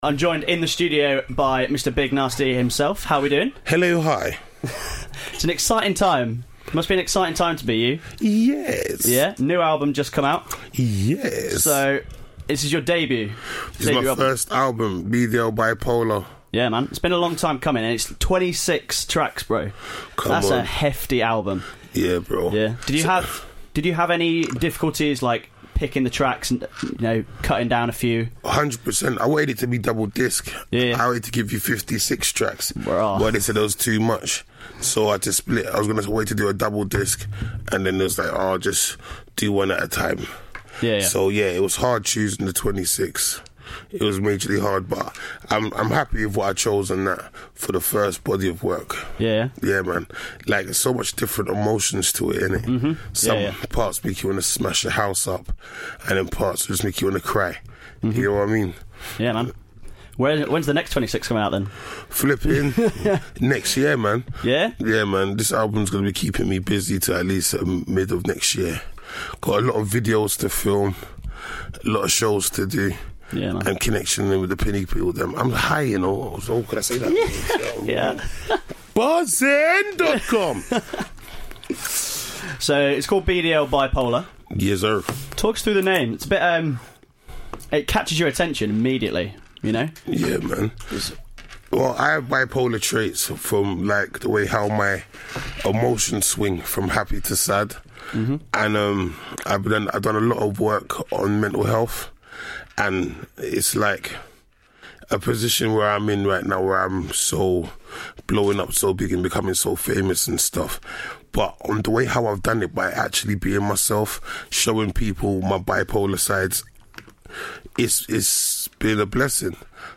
Ahead of his gig at Rough Trade Nottingham, Big Narstie swung by our studio to discuss his debut album, BDL Bipolar, and his new TV show, The Big Narstie Show.